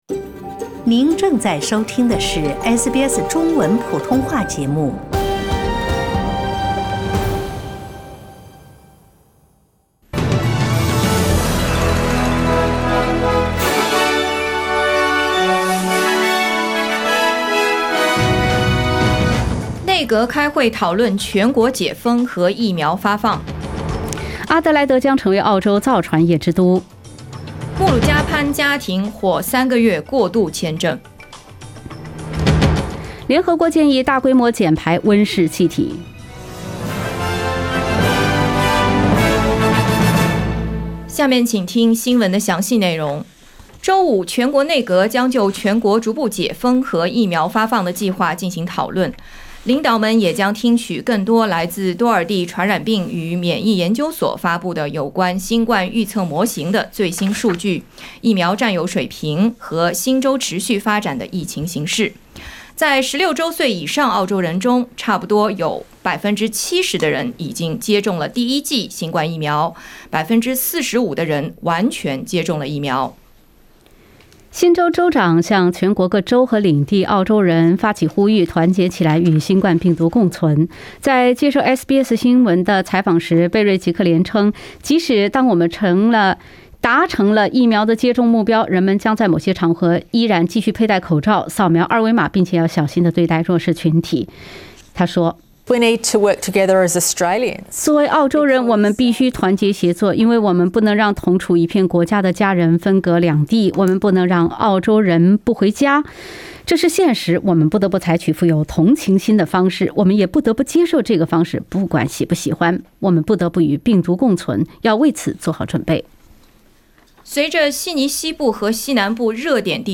SBS早新聞（9月17日）